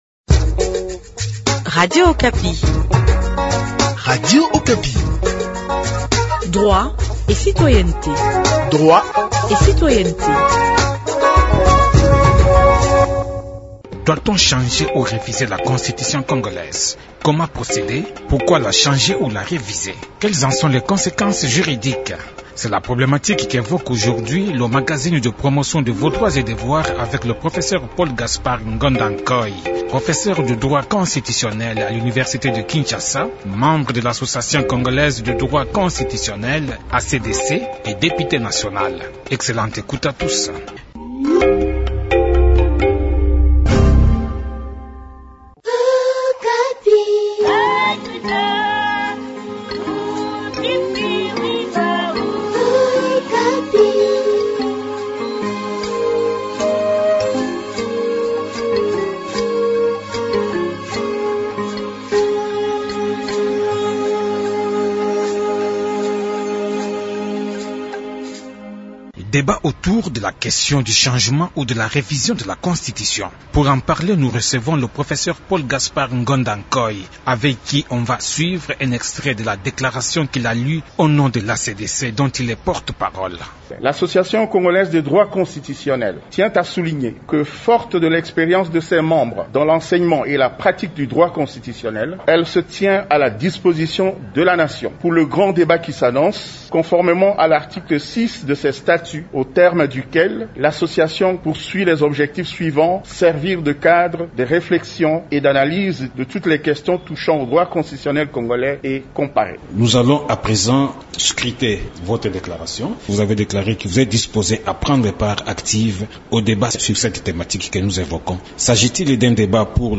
C’est la problématique qu’évoque le magazine de promotion des droits et devoirs avec le professeur Paul-Gaspard Ngondankoy, professeur de droit constitutionnel à l’Université de Kinshasa, membre de l’Association congolaise de droit constitutionnel et député national. A l’en croire, on ne peut pas réviser une constitution en touchant à ses articles verrouillés.
Il commente avec nous la thématique , à la lumière de son expérience et des enseignements de droit constitutionnel.